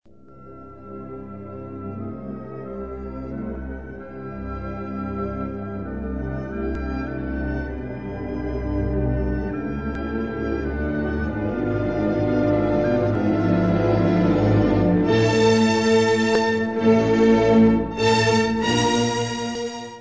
Concerto pour violon & orchestre
Allegro non troppo